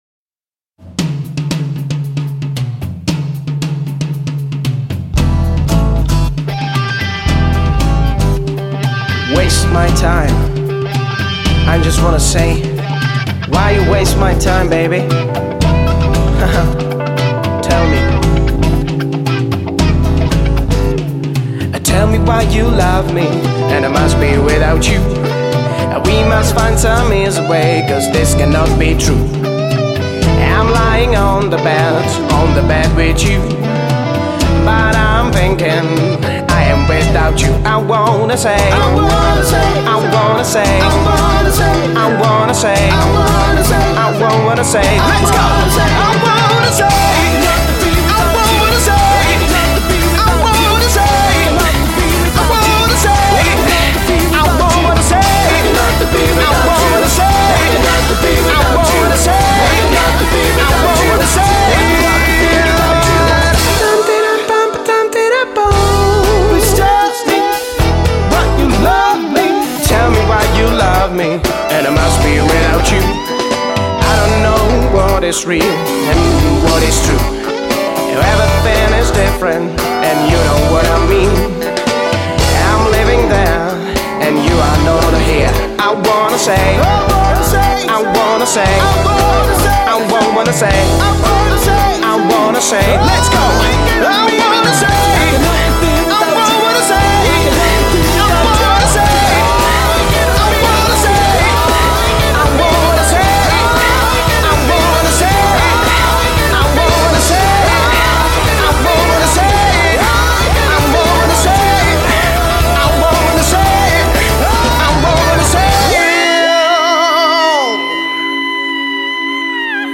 Žánr: Pop
CD bylo nahráno ve známém ostravském studiu Citron.